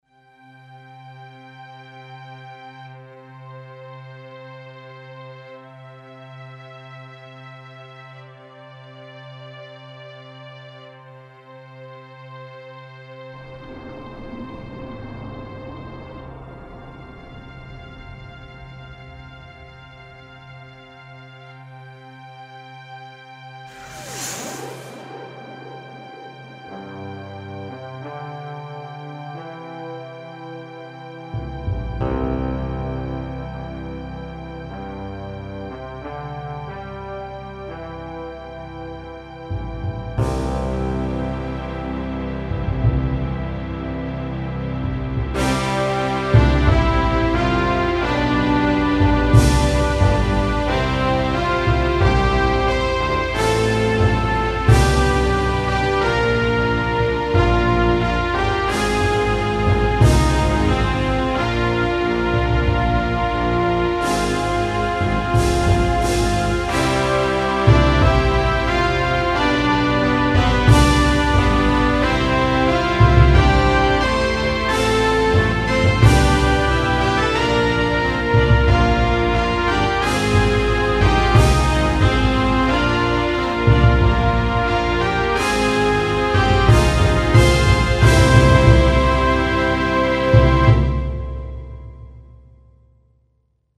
Back then I composed a tiny theme for this “series”, which obviously is in the style of the classic series like Voyager or Deep Space Nine.